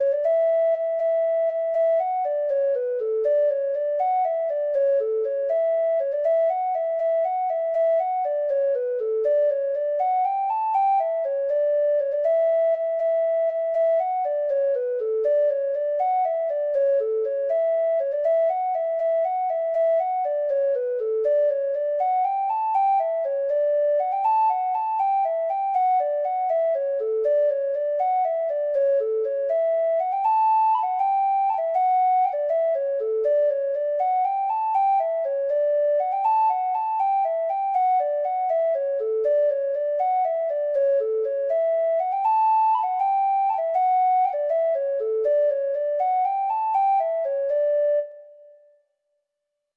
Free Sheet music for Treble Clef Instrument
Traditional Music of unknown author.
Irish